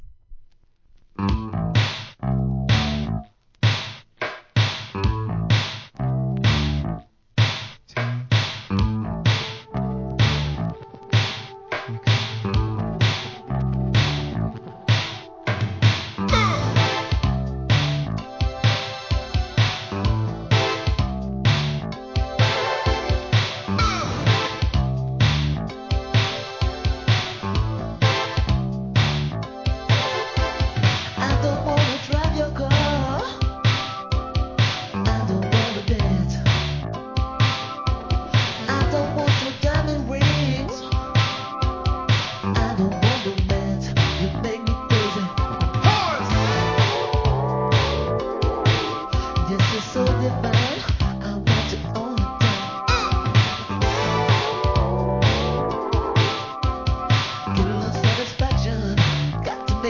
店舗 数量 カートに入れる お気に入りに追加 1985年、エレクトロFUNK!!